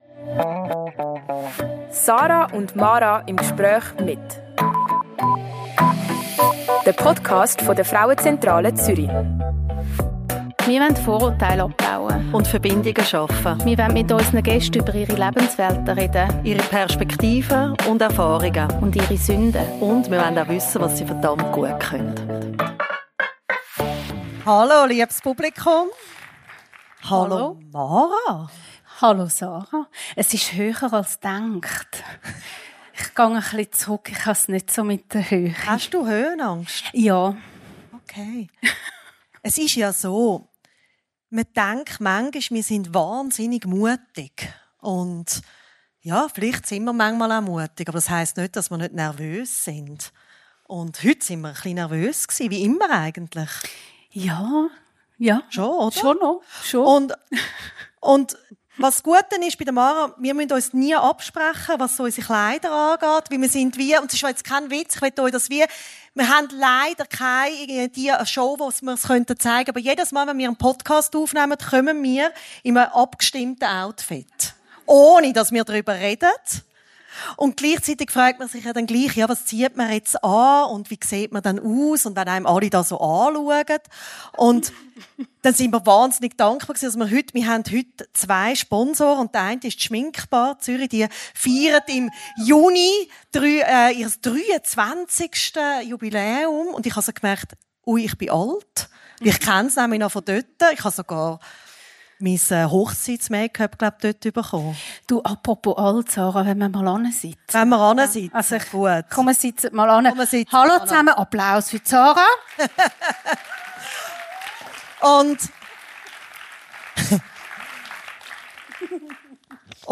Der Live-Podcast im Kaufleuten wird noch lange nachhallen. Der Saal war voll, die Energie spürbar und die Gespräche tief, ehrlich und unglaublich verbindend.